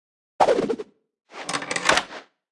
Media:Sfx_Anim_Ultimate_Archer Queen.wav 动作音效 anim 在广场点击初级、经典、高手、顶尖和终极形态或者查看其技能时触发动作的音效
Sfx_Anim_Ultra_Archer_Queen.wav